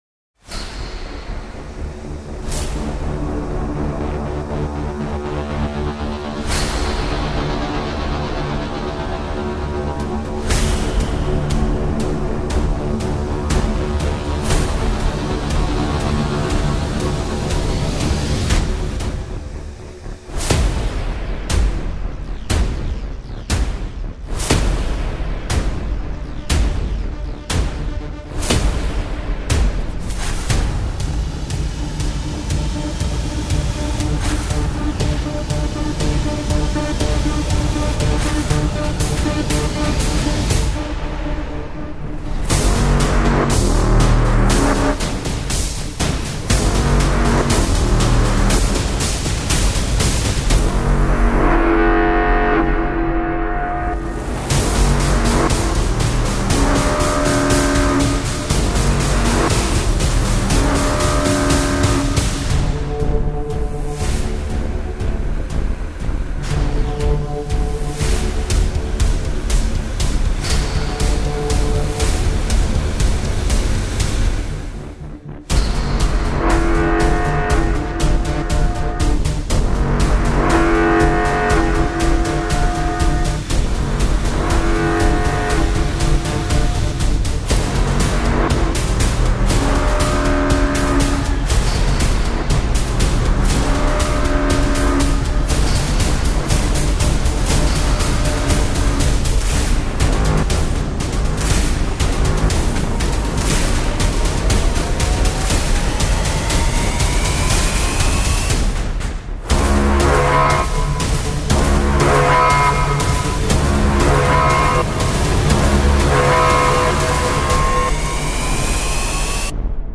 Hybrid track for trailers.
Hybrid track drums, hits and risers driven for trailers.